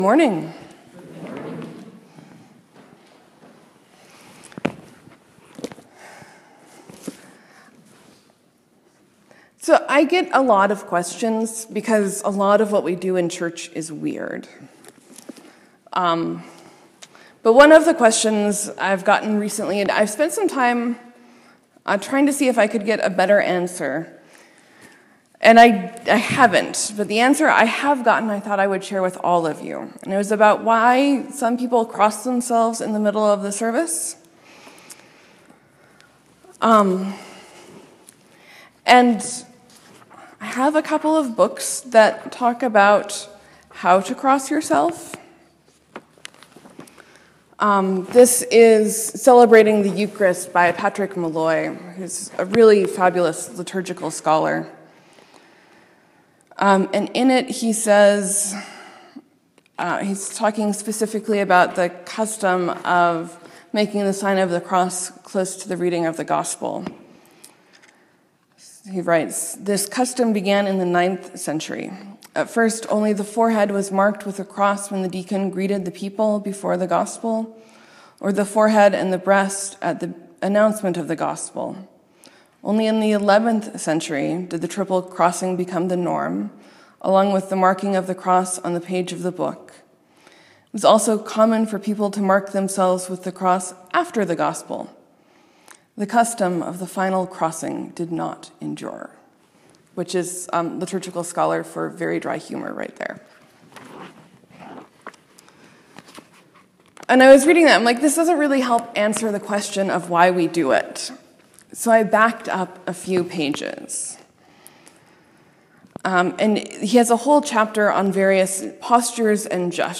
Sermon: Jesus says calls Peter a satan and a stumbling block and I hear echoes from the rest of the Bible.